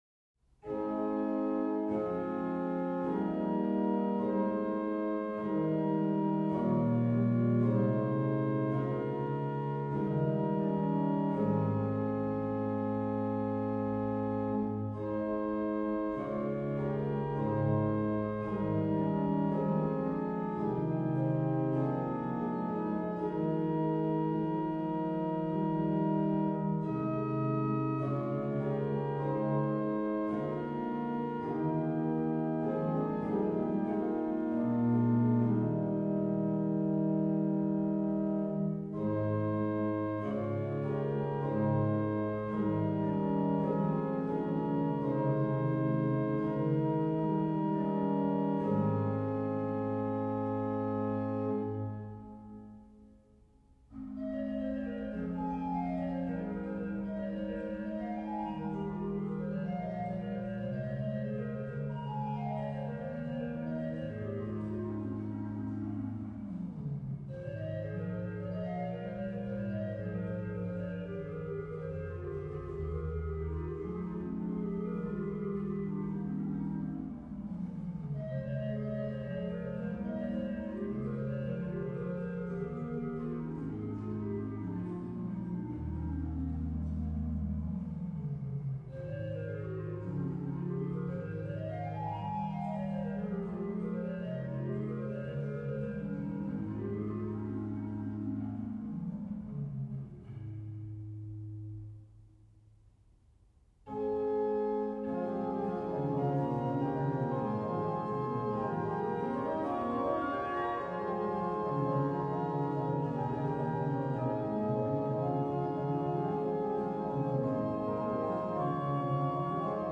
rh: BW: Fl8, Fl4
BW: Fl8, Oct4, Oct2, 1 1/2